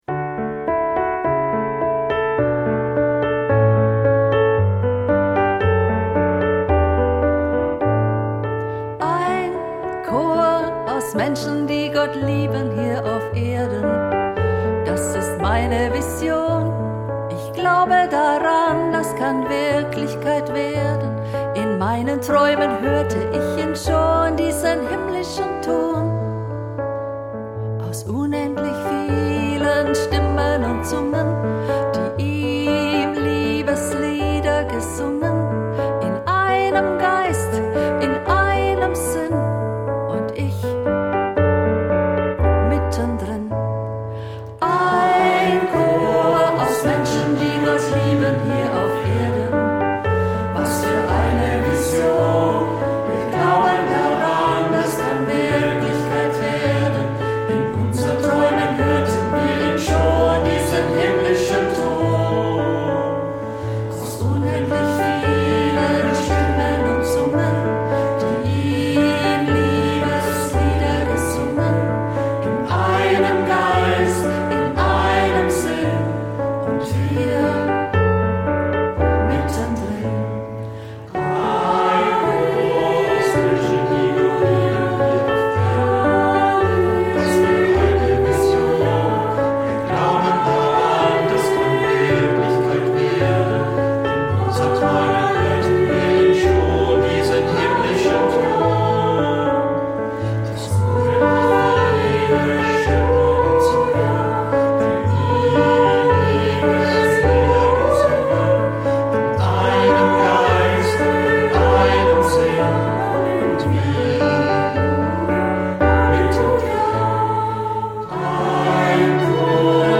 Klavier und Gesang
Chor: Jugendchor der Zellerau/Würzburg